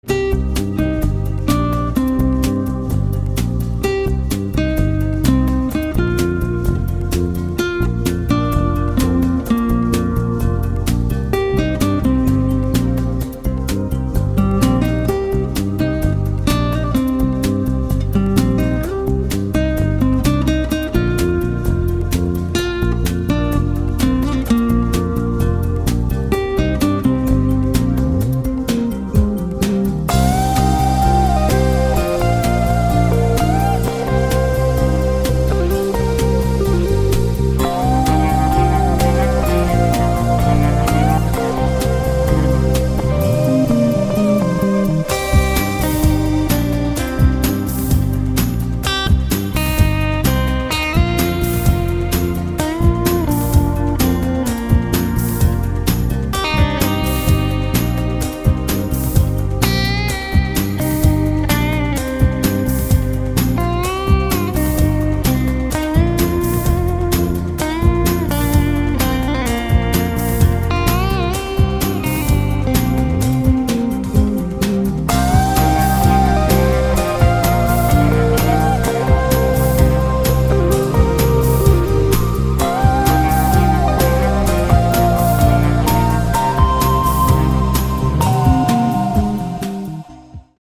• Качество: 160, Stereo
гитара
спокойные
без слов
chillout
инструментальные
электрогитара
New Age